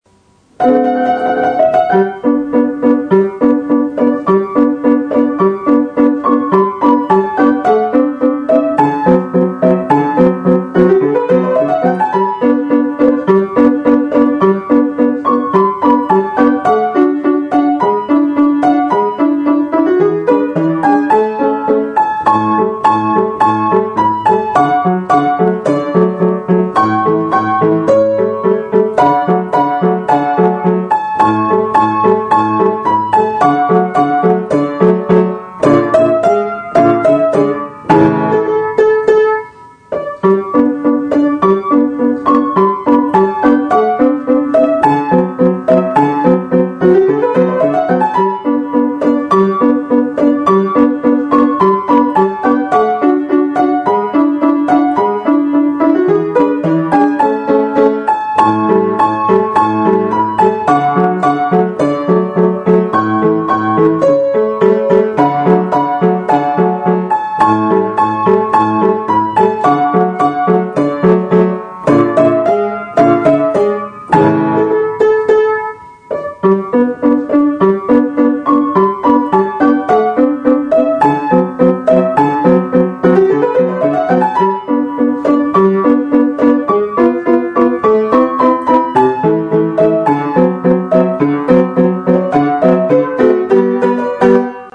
Штраус Трик-трак для оркестра